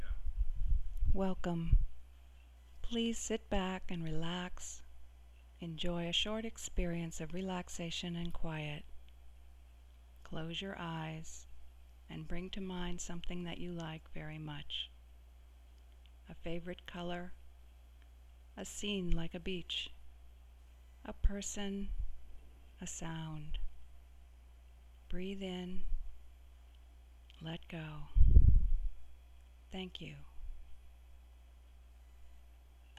relaxation exercise